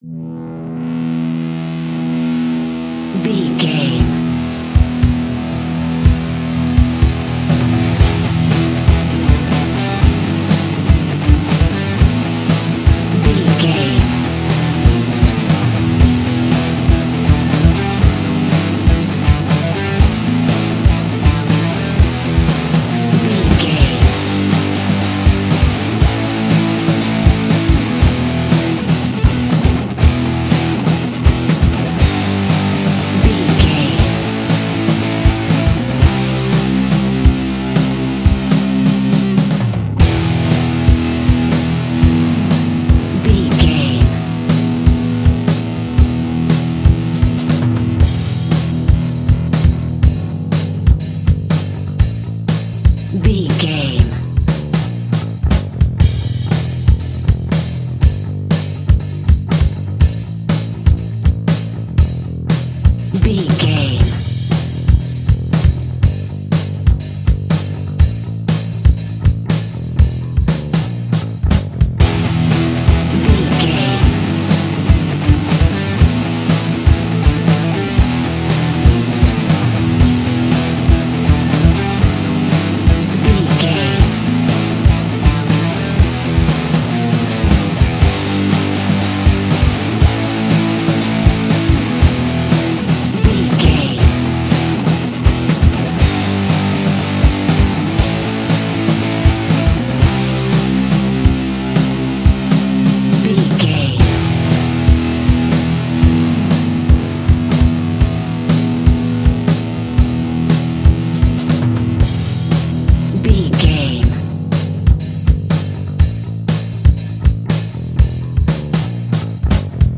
Aeolian/Minor
hard
groovy
powerful
electric guitar
bass guitar
drums
organ